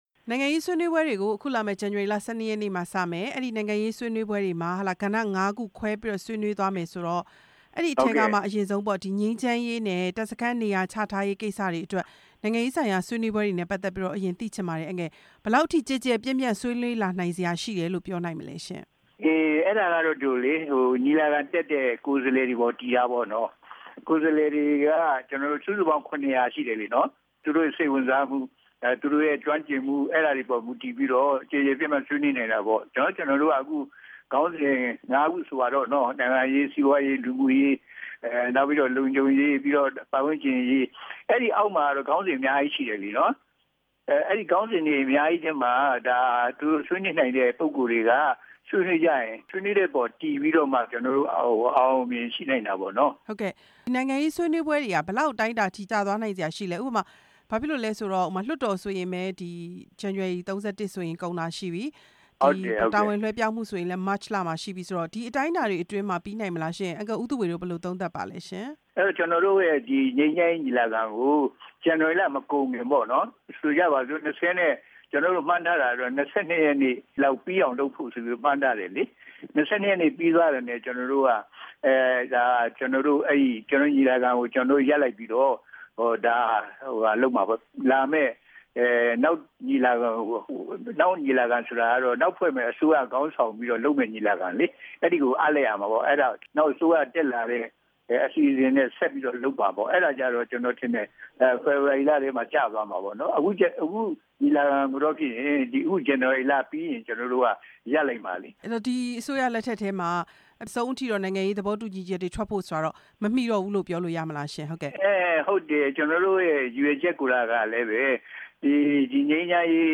ပြည်ထောင်စု ငြိမ်းချမ်းရေးညီလာခံနဲ့ ပတ်သက်လို့ မေးမြန်းချက်